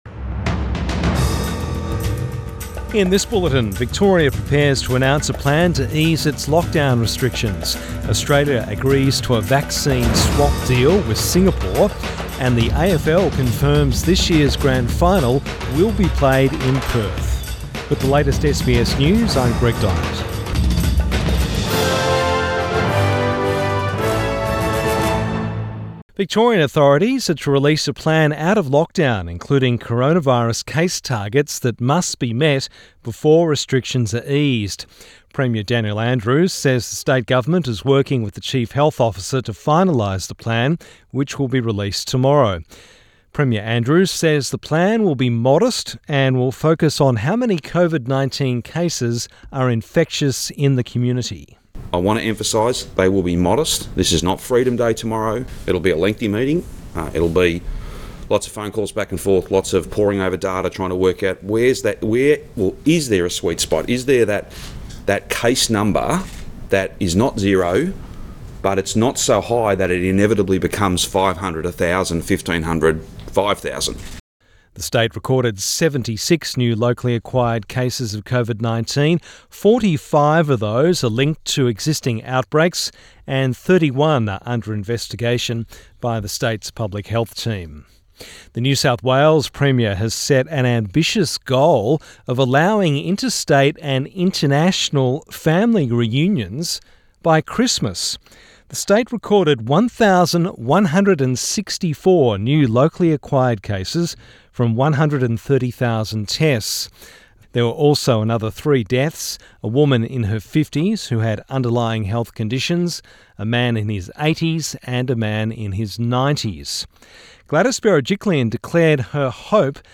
PM bulletin 31 August 2021